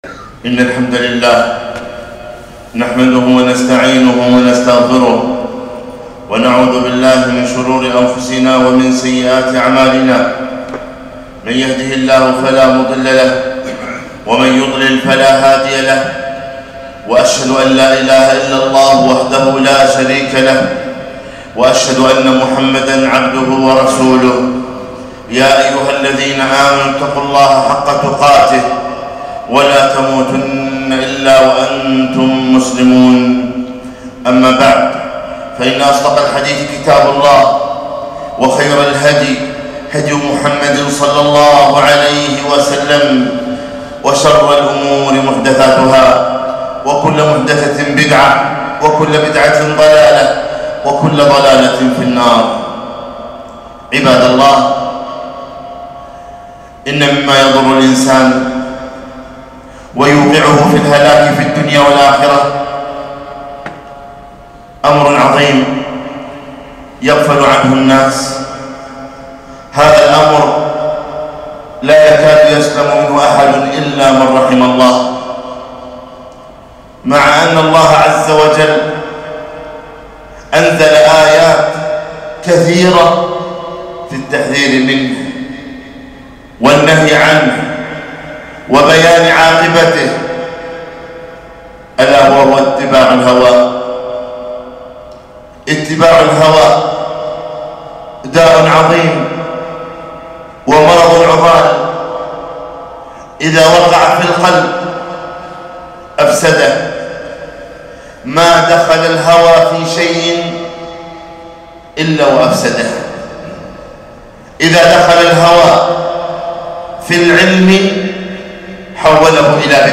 خطبة - ولا تتبع الهوى فيضلك عن سبيل الله